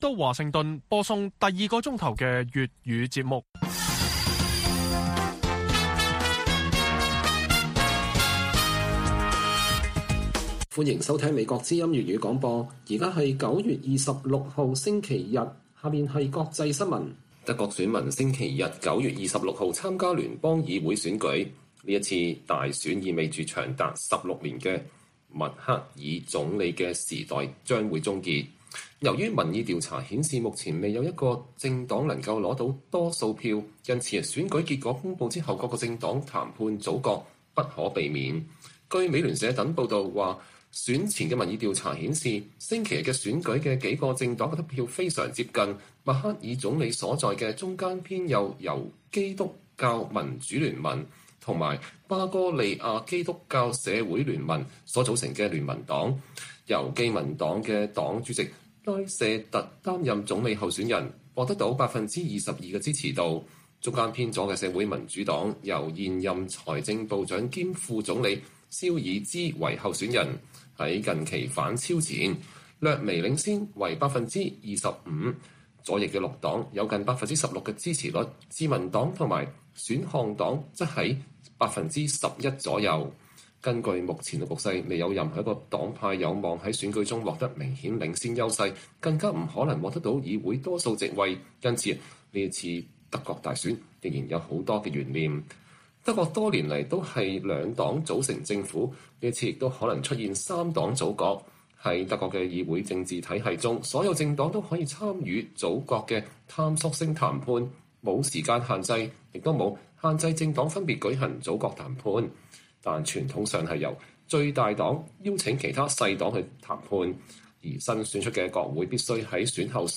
粵語新聞 晚上10-11點: Quad雖不完美 但不妨礙日本是核心平衡要角